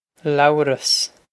Low-russ
This is an approximate phonetic pronunciation of the phrase.